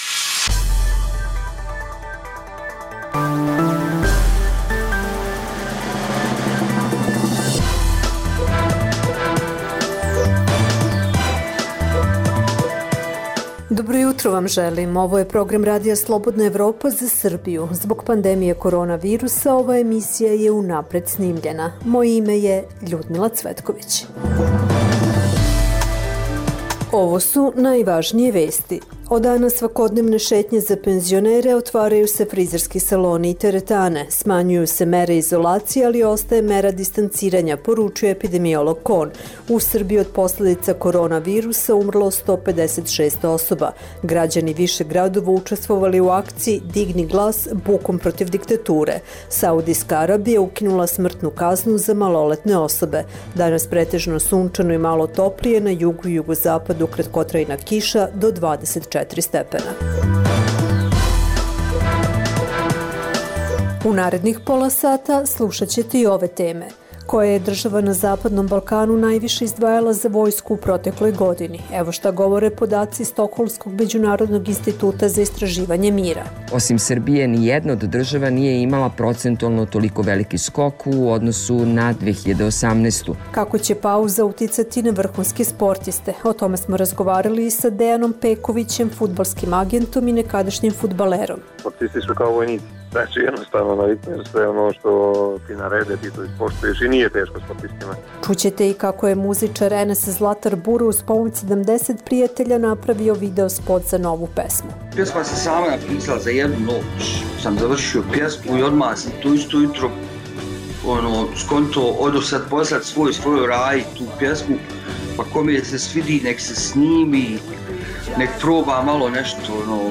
Zbog pandemije korona virusa ova emisija je unapred snimljena. Od danas svakodnevne šetnje za penzionere, otvaraju se frizerski saloni i teretane.